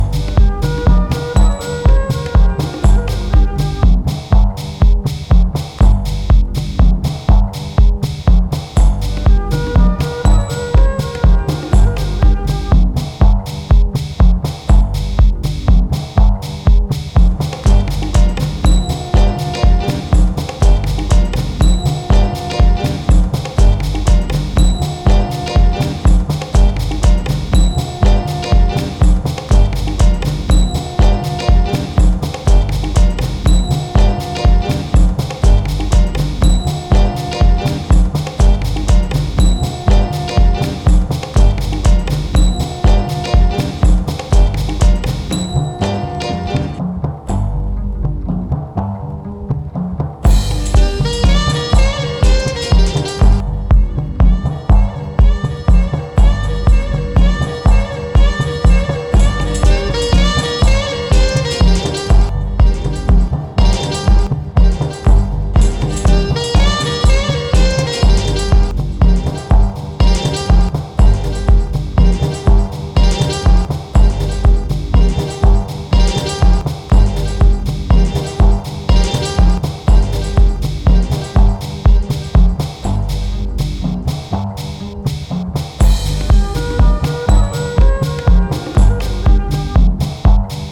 3/4拍子のオーケストラ・サンプルが催眠的な